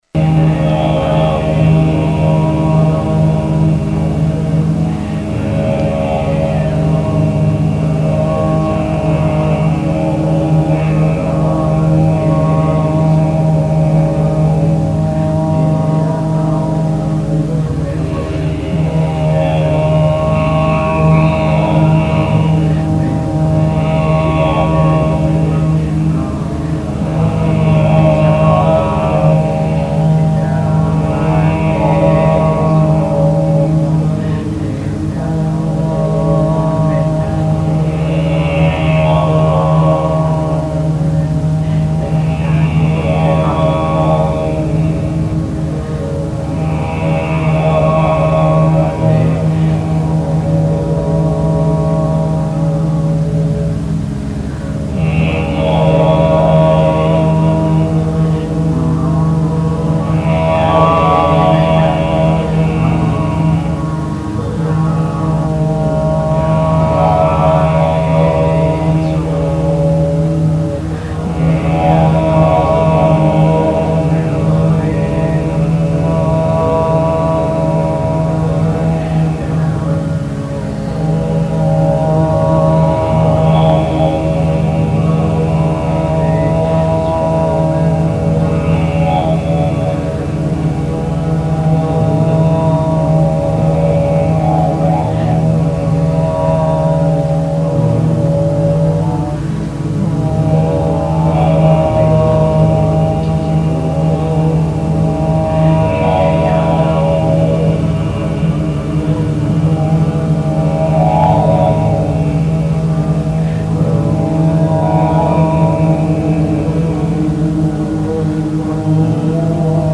Trích Đoạn Tụng Chú